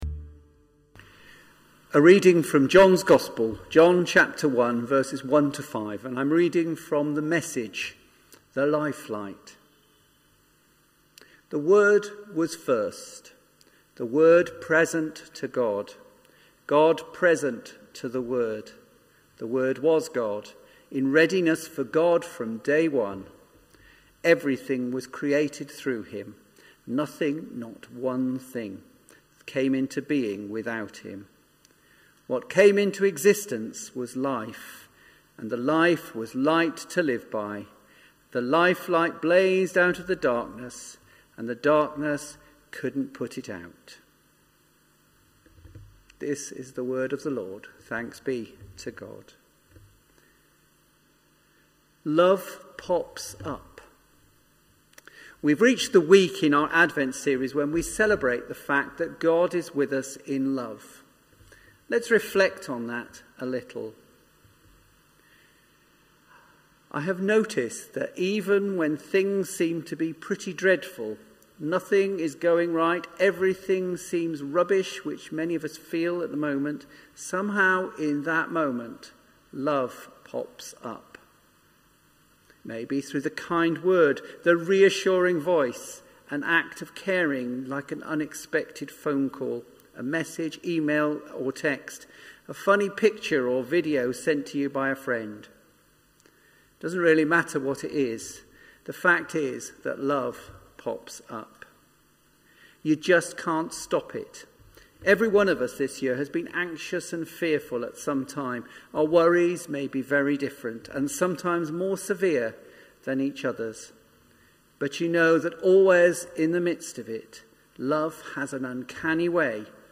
latestsermon-1.mp3